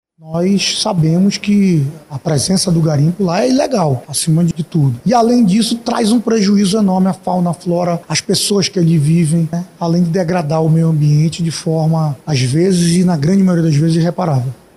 Sonora-1-Caio-Andre-–-presidente-da-CMM.mp3